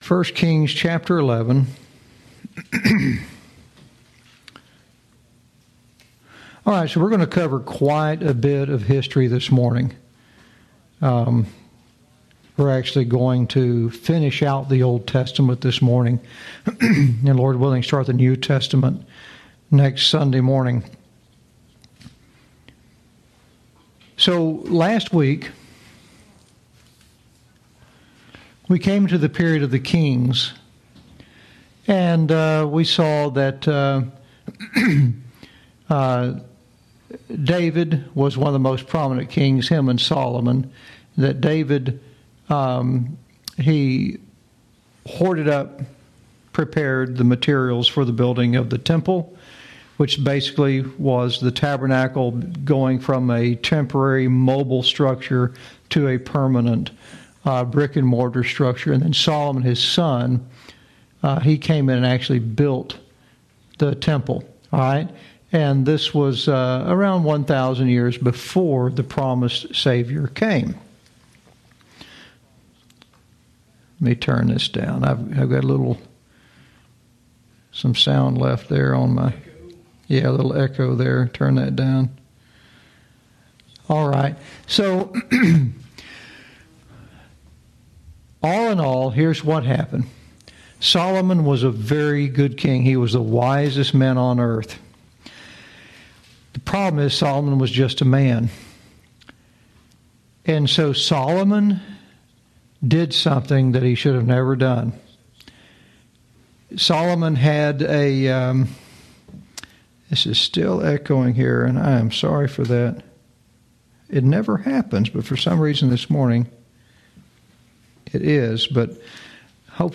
Lesson 31